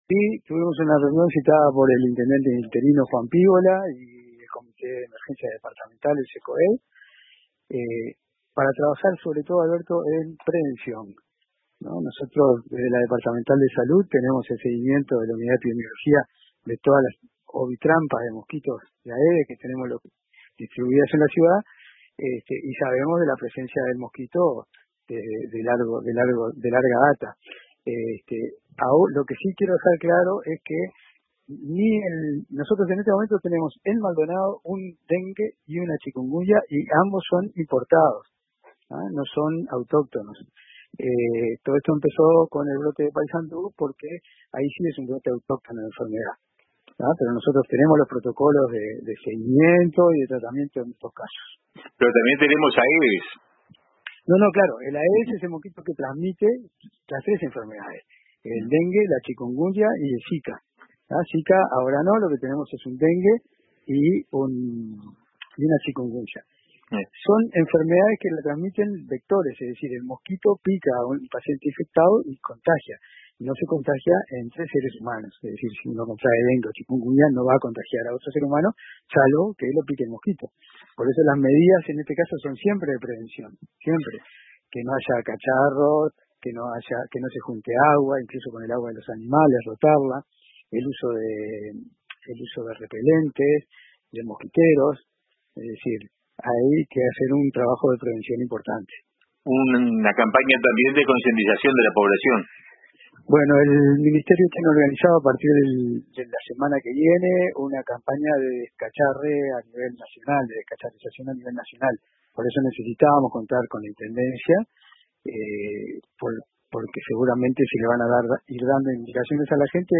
Tras el encuentro, se designó como vocero al Director Departamental de Salud, Dr. Pablo García Da Rosa, quien habló con RADIO RBC.